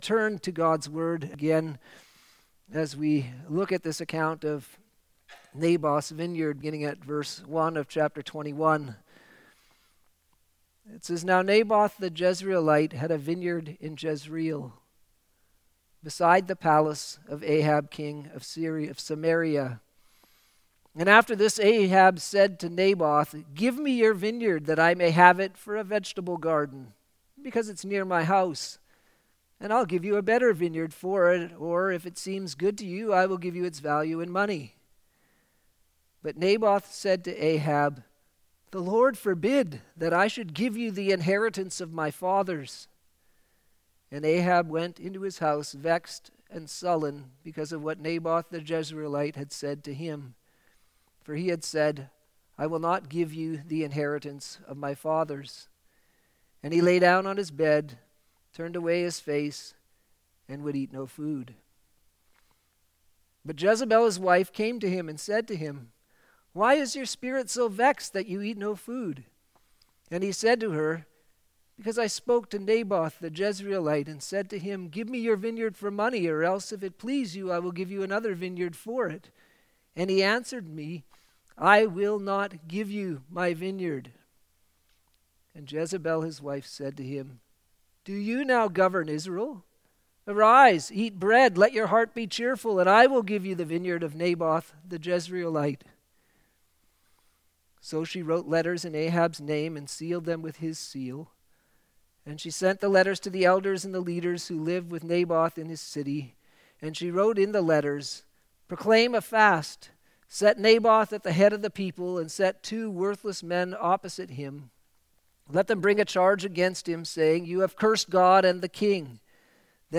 Sermons
Sunday Evening Service - 1 Kings 21:1-16